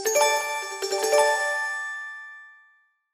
SMSに最適な通知音です。
可愛いベル音。